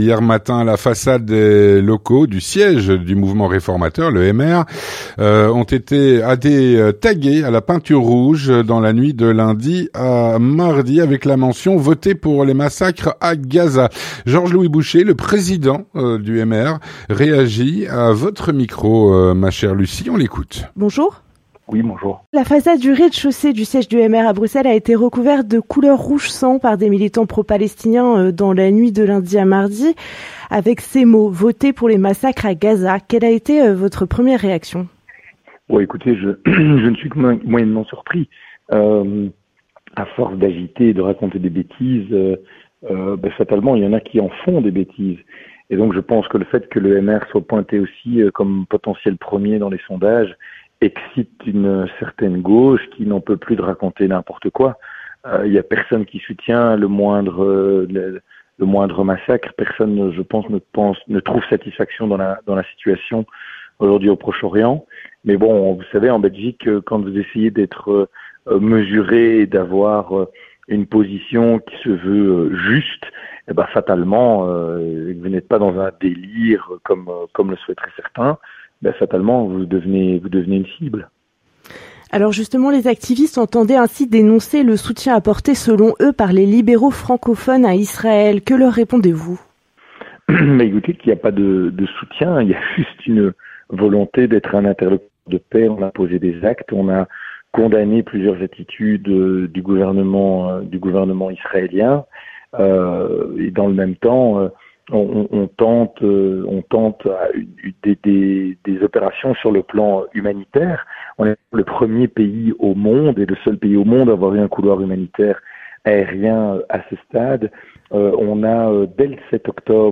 L'entretien du 18H - La façade des locaux du MR ont été tagués à la peinture rouge, avec la mention “votez pour les massacre à Gaza". Avec Georges-Louis Bouchez (29/05/2024)
Avec Georges-Louis Bouchez, Président du MR.